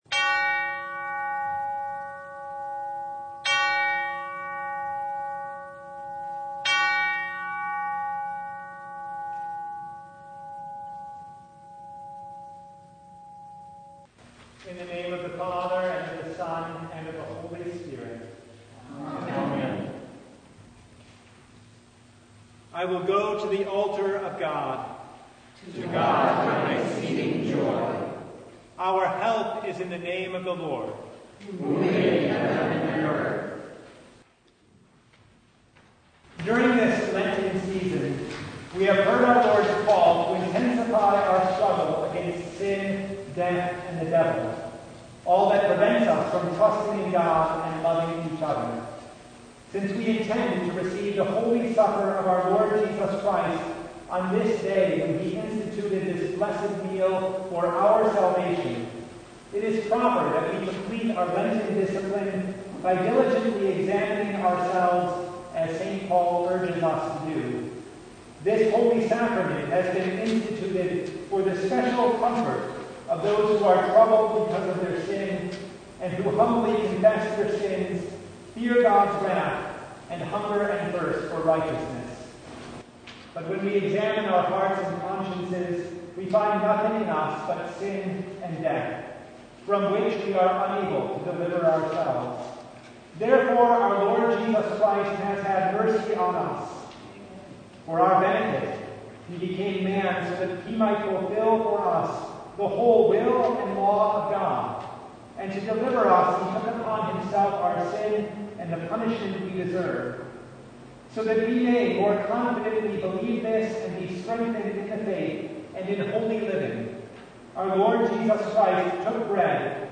Maundy Thursday Service (audio recording)
Service Type: Maundy Thursday